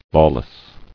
[law·less]